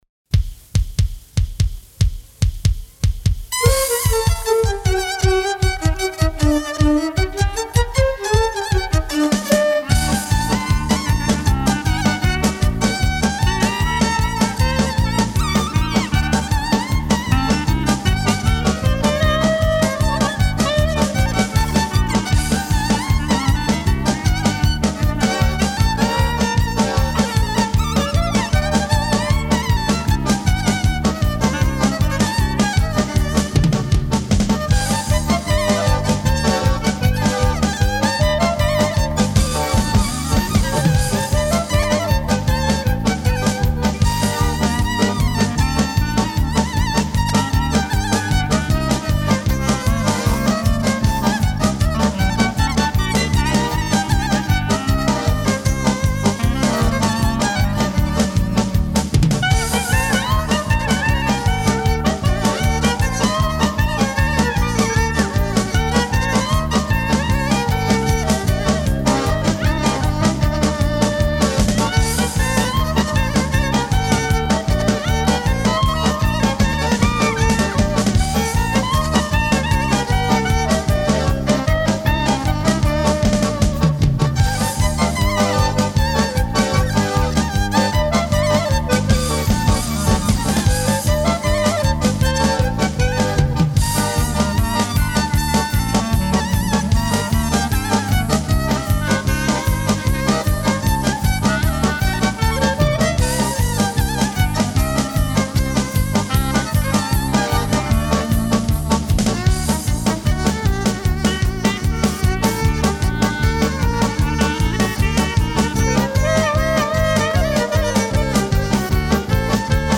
Instrumental, Klezmer